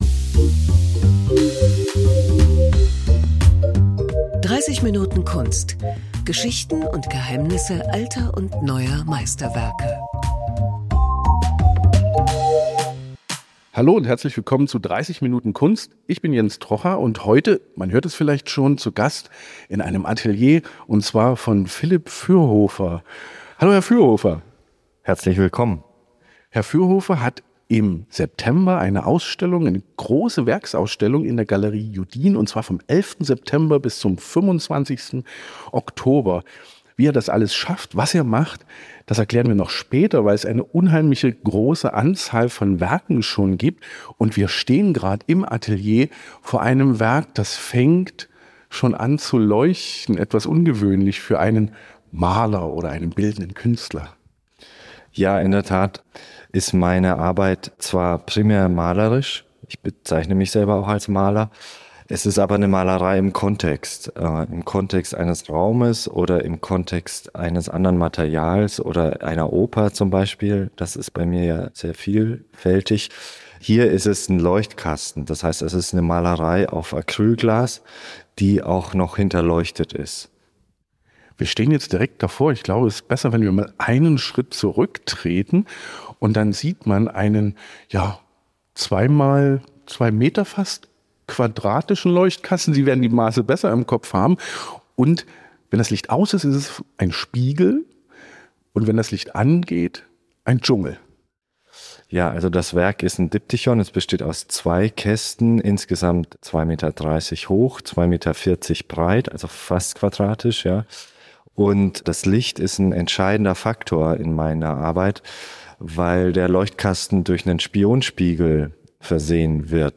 Bei einem Atelierbesuch spricht er über seine Vorstellungen von Kunst und die Umsetzung in Bilder. Wie gelingt es ihm, Illusionen zu schaffen, die real werden?